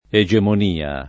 egemonia [ e J emon & a ] s. f.